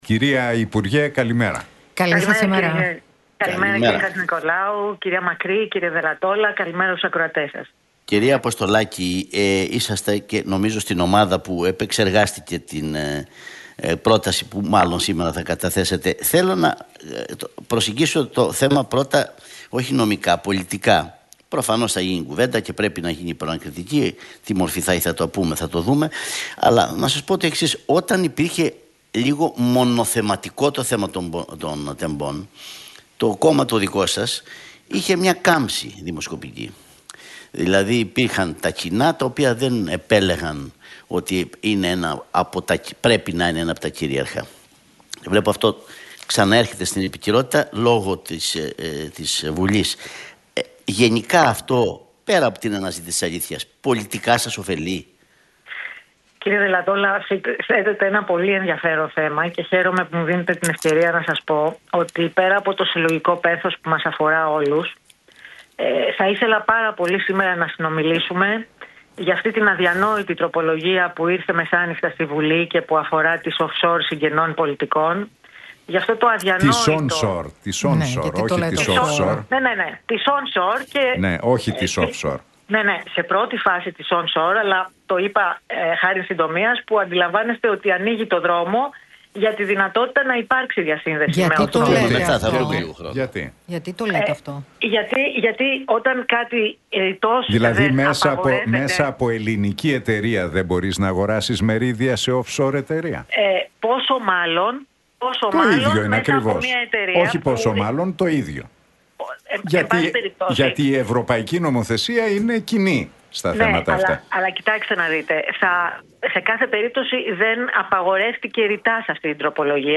Αποστολάκη στον Realfm 97,8 για Προανακριτική: Το περιεχόμενο της πρότασης του ΠΑΣΟΚ θα είναι απολύτως στηριγμένο και εναρμονισμένο στο κατηγορητήριο